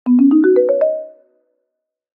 bingo_board2.ogg